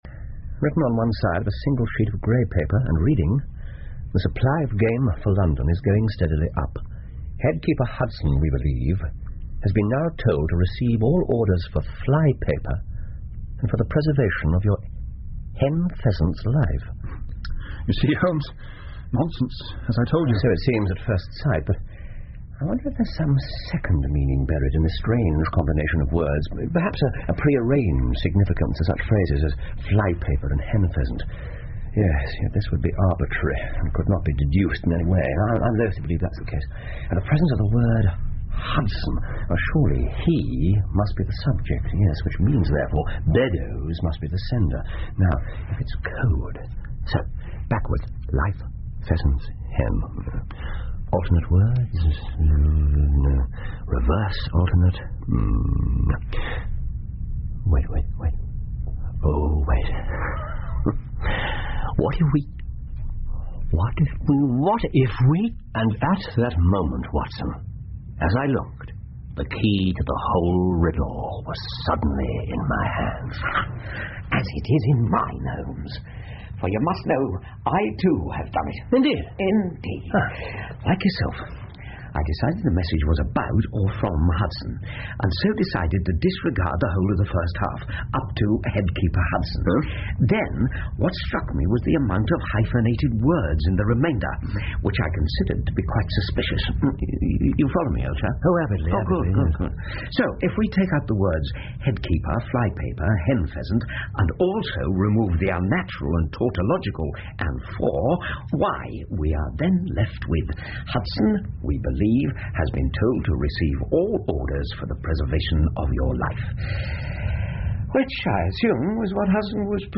福尔摩斯广播剧 The Gloria Scott 6 听力文件下载—在线英语听力室